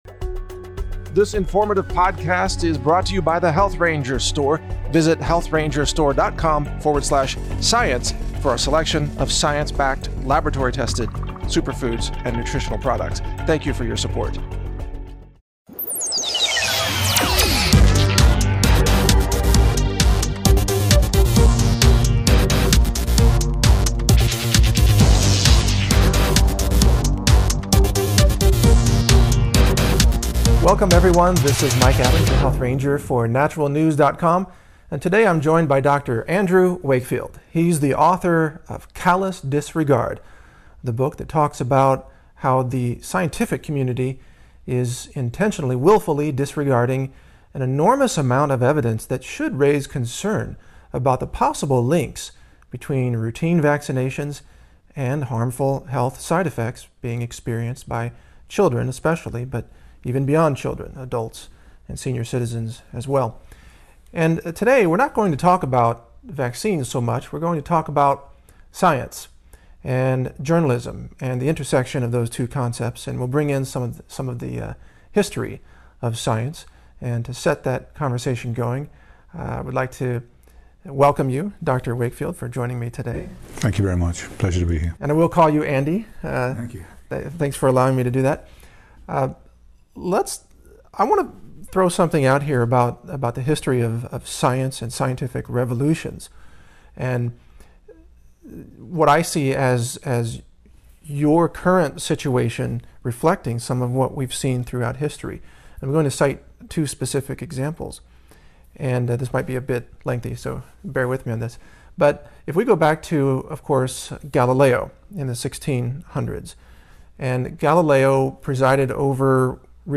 HRR-interview-with-dr-andrew-wakefield-the-structure-of-scientific-revolutions.mp3